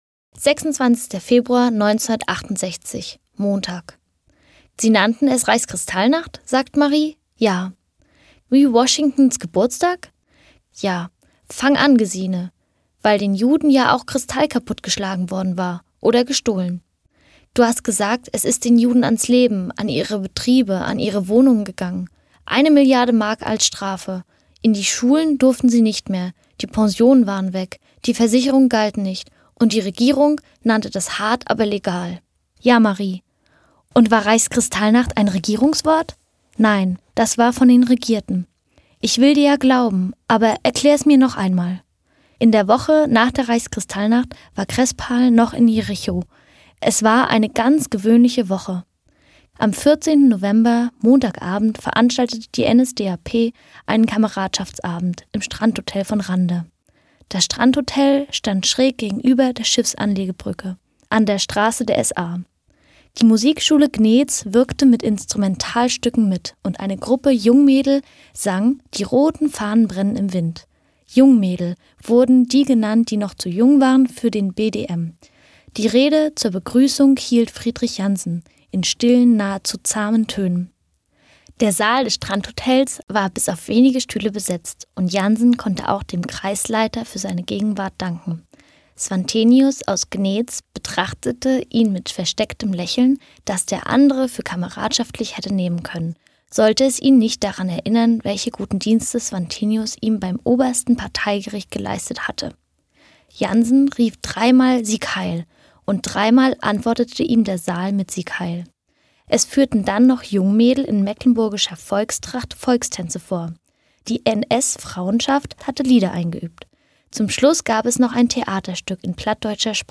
Eine Stadt liest Uwe Johnsons Jahrestage - 26.